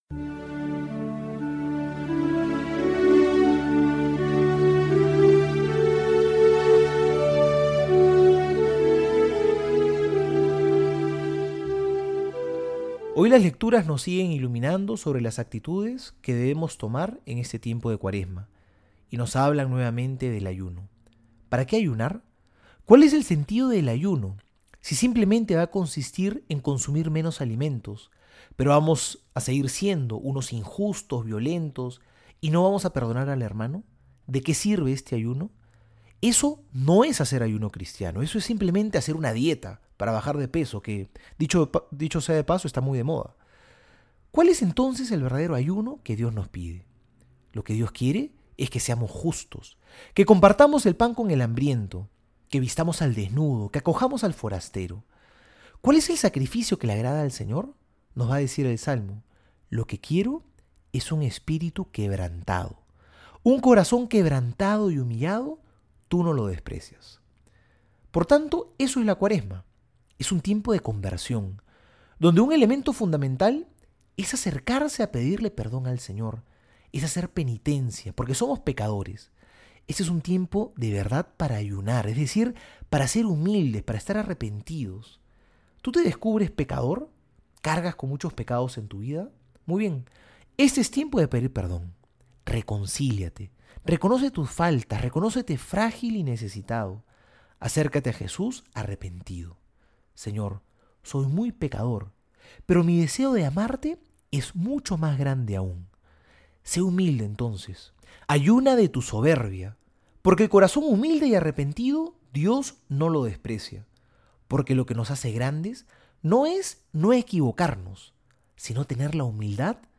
Homilía para hoy: